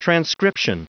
Prononciation du mot transcription en anglais (fichier audio)
Prononciation du mot : transcription